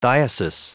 Prononciation du mot diocese en anglais (fichier audio)
Prononciation du mot : diocese